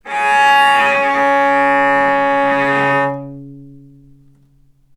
vc_sp-C#3-ff.AIF